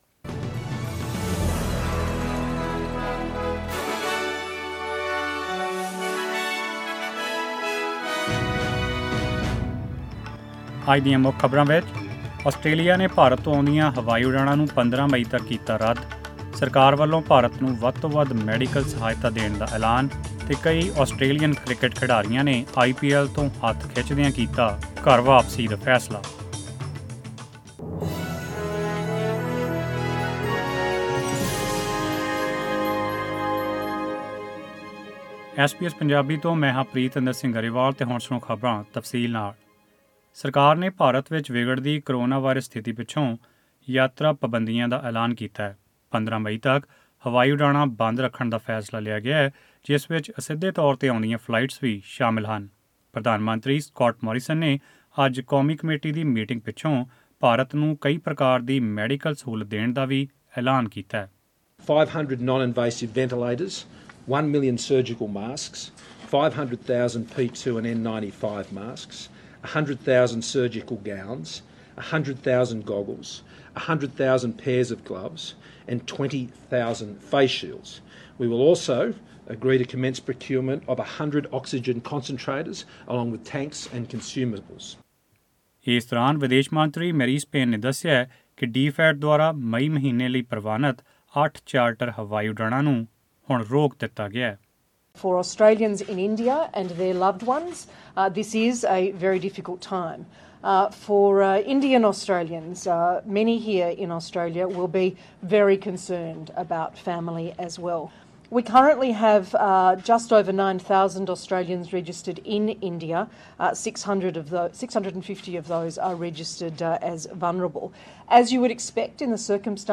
The Federal Government is suspending all passenger flights from India to Australia until at least the 15th of May. Restrictions have also been imposed on indirect flights. This and more in tonight’s news bulletin…